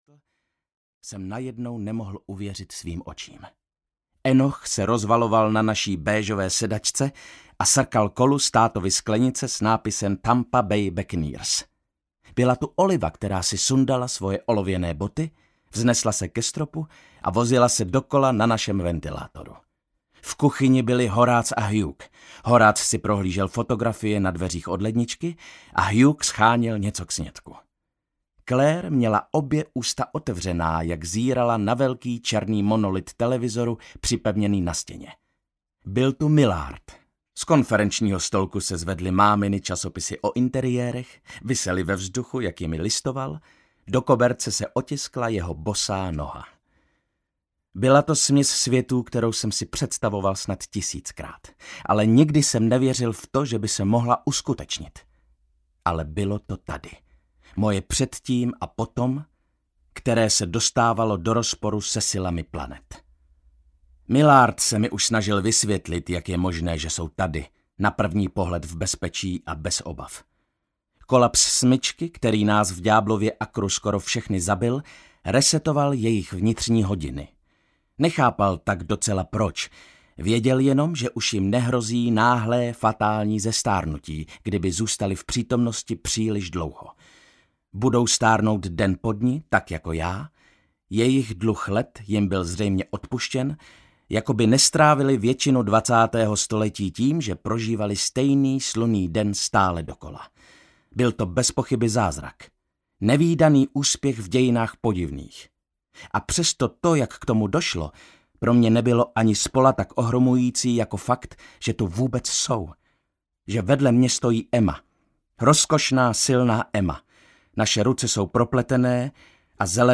Sirotčinec slečny Peregrinové: MAPA DNÍ audiokniha
Ukázka z knihy
• InterpretViktor Dvořák